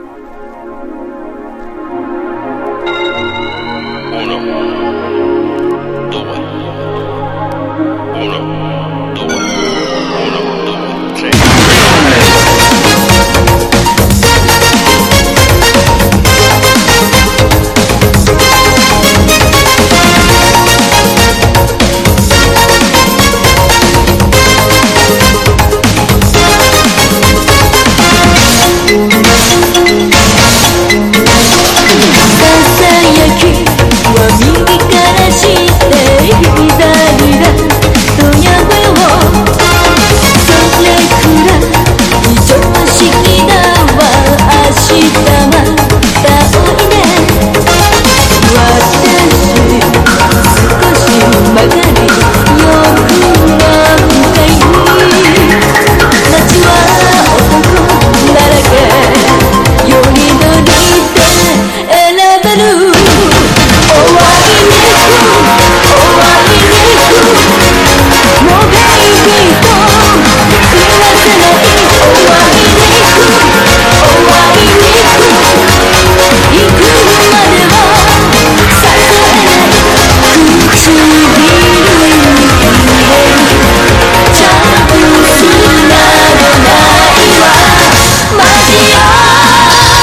# POP# CITY POP / AOR# 和モノ / ポピュラー# 70-80’S アイドル